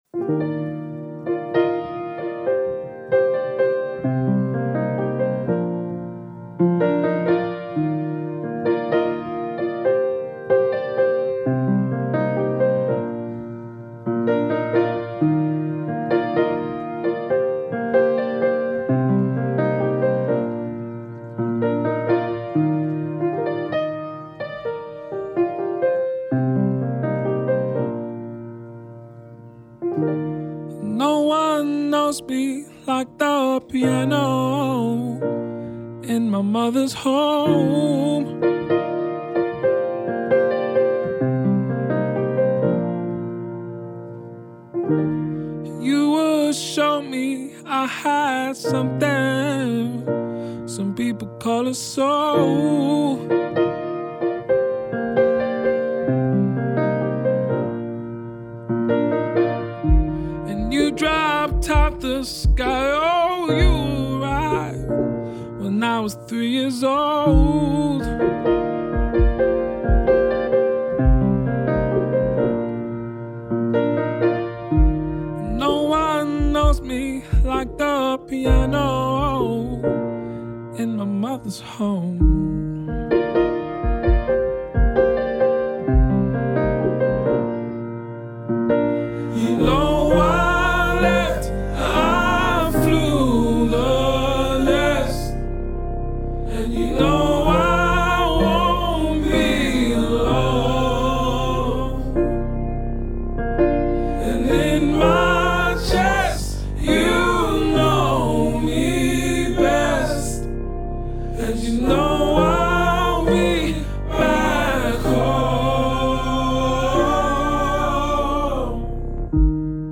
Listen in as she talks her way through her most eclectic new music playlist so far!